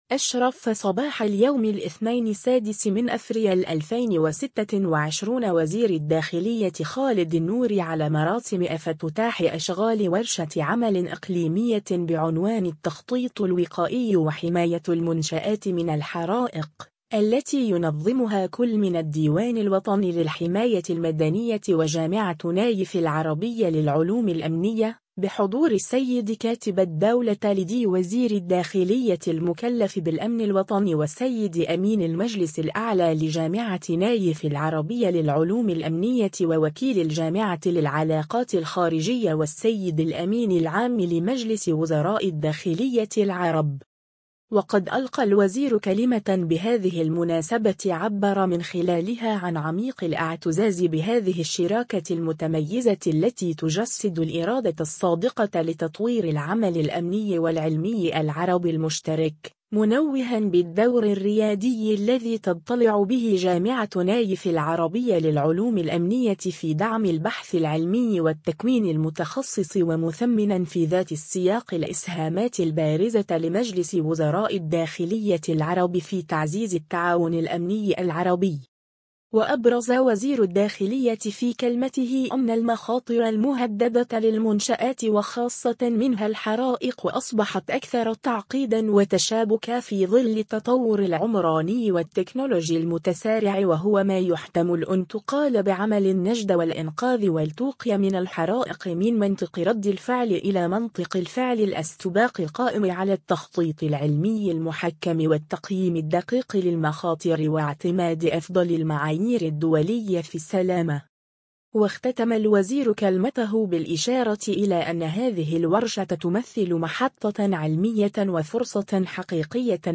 وقد ألقى الوزير كلمة بهذه المُناسبة عبّر من خلالها عن عميق الإعتزاز بهذه الشراكة المُتميزة التي تُجسّدُ الإرادة الصّادقة لتطوير العمل الأمني والعلمي العربي المُشترك، منوها بالدّور الرّيادي الذي تضطلعُ به جامعة نايف العربيّة للعُلوم الأمنيّة في دعم البحث العلمي والتكوين المُتخصّص ومُثمّنا في ذات السّياق الإسهامات البارزة لمجلس وُزراء الدّاخليّة العرب في تعزيز التعاون الأمني العربي.